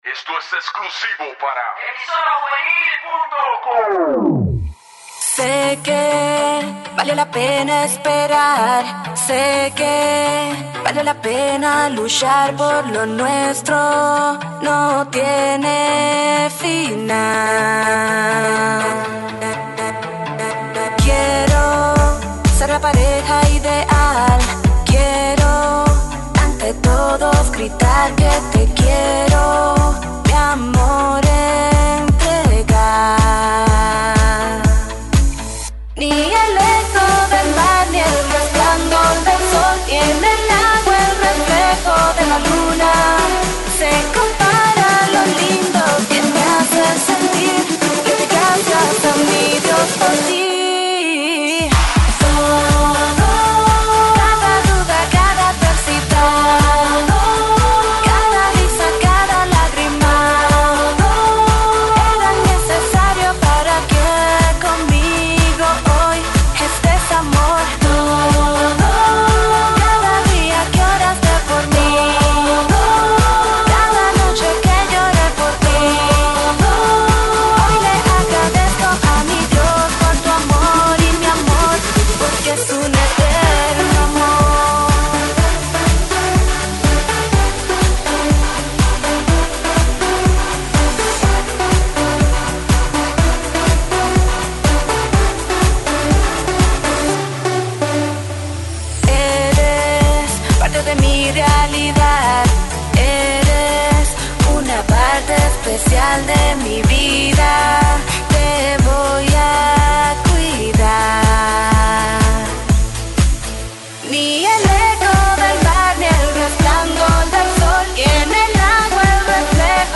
Música Cristiana
una joven dominicana y cantante de música Urbana